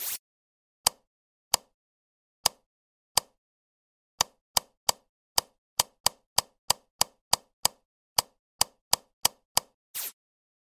Among Us Fixing Light Sound Effect Free Download